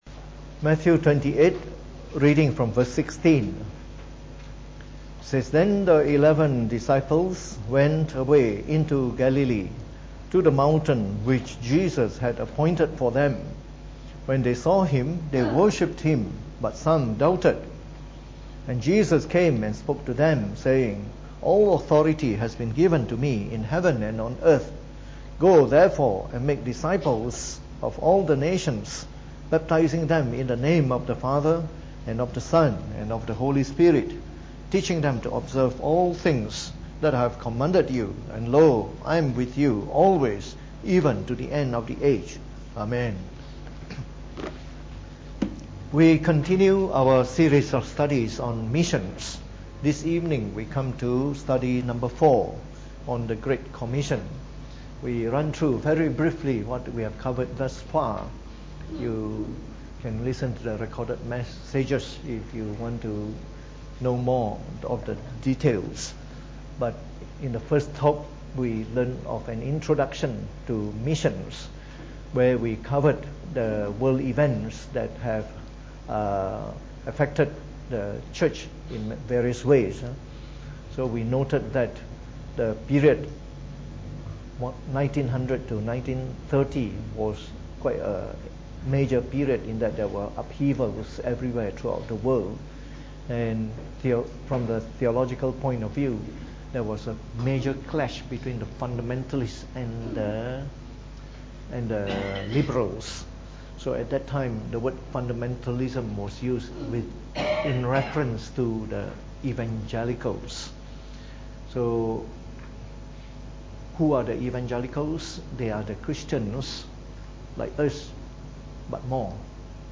Preached on the 28th of November 2018 during the Bible Study, from our series on Missions.